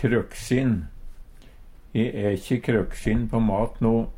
krøksin - Numedalsmål (en-US)